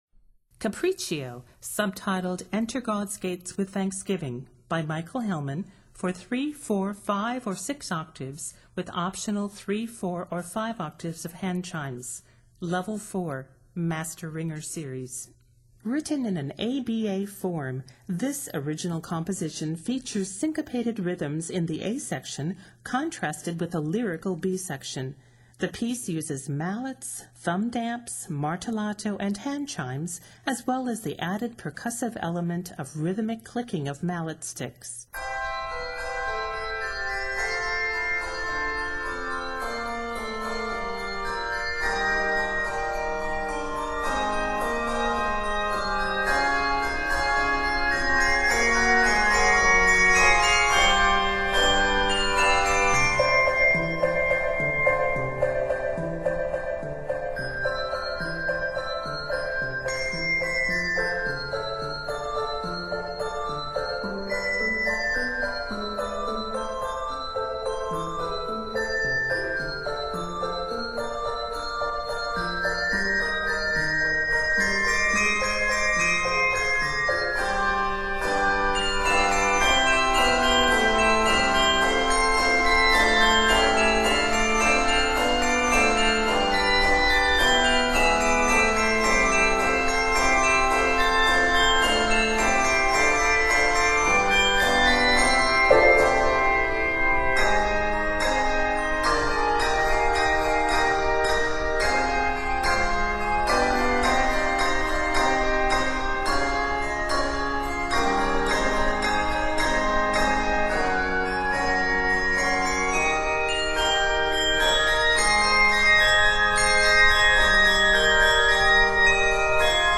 An energetic original composition